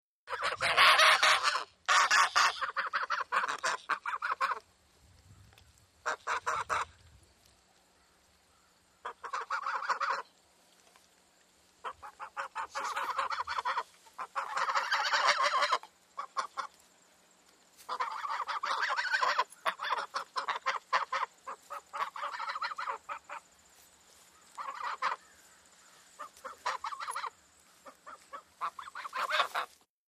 Geese alarmed & chattering ( close )